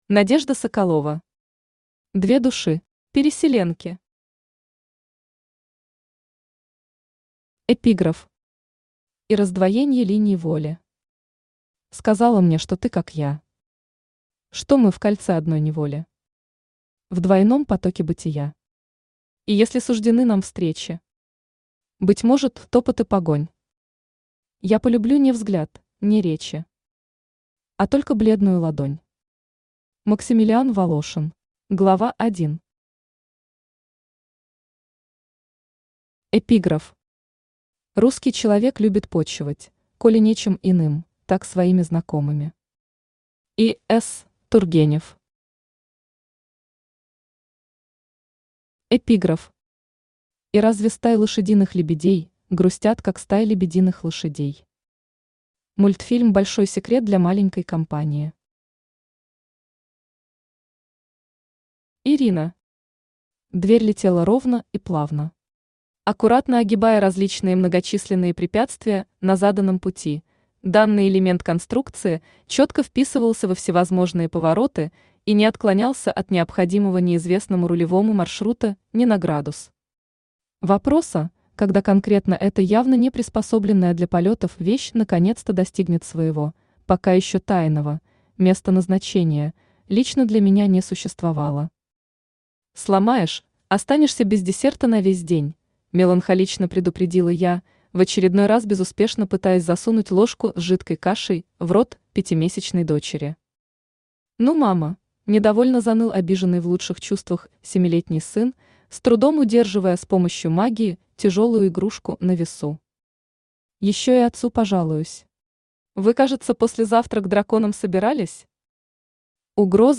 Аудиокнига Две души. Переселенки | Библиотека аудиокниг
Переселенки Автор Надежда Игоревна Соколова Читает аудиокнигу Авточтец ЛитРес.